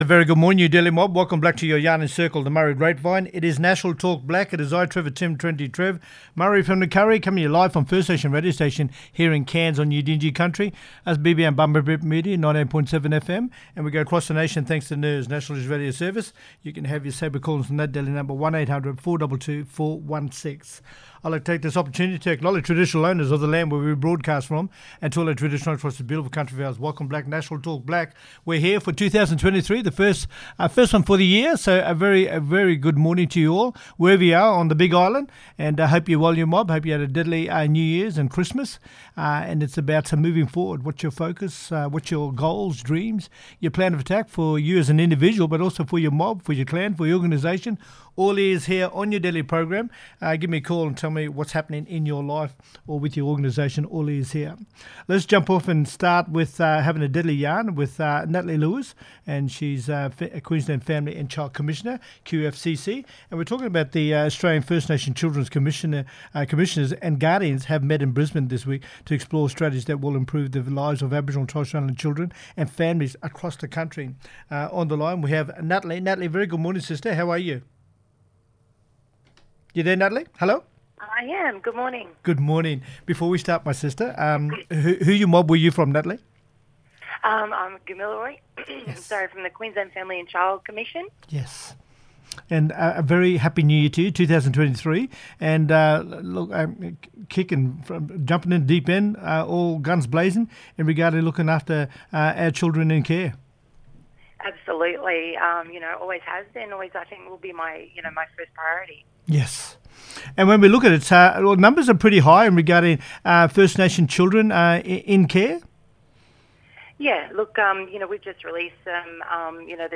Queensland Family and Child Commission (QFCC) Commissioner Natalie Lewis, talking about the First Nations children’s commissioners focus on kids in care. Australian First Nations children’s commissioners and guardians met in Brisbane last week to explore strategies that will improve the lives of Aboriginal and Torres Strait Islander children and families across the country.